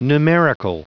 Prononciation du mot numerical en anglais (fichier audio)